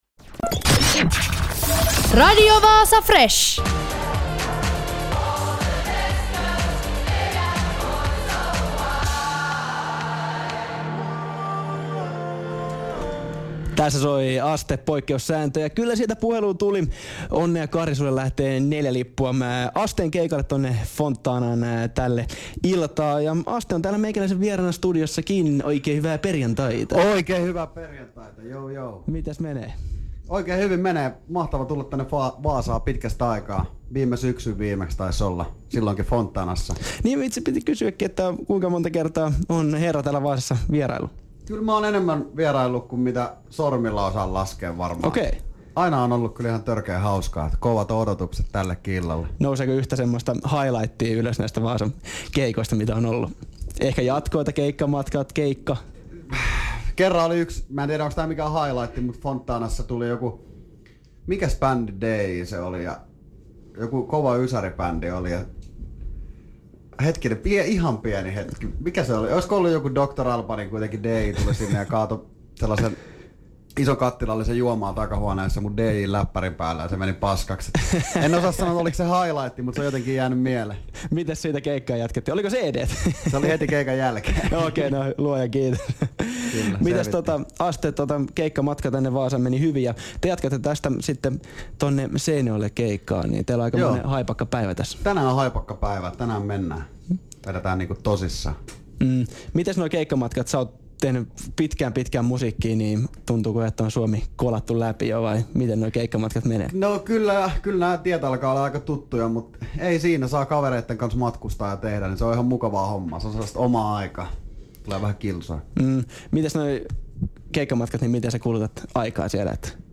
Radio Vaasa FRSH: Studiossa vieraana Aste
Aste-haastis.mp3